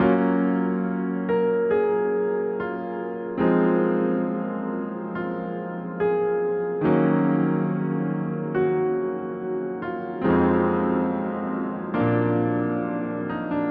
Soft Piano Melody
描述：Used the Grand Piano FL Keys preset, added a little reverb. Chords are Fmaj7, Fmin7, Dmin7, and A7.
标签： 70 bpm Jazz Loops Piano Loops 2.31 MB wav Key : C FL Studio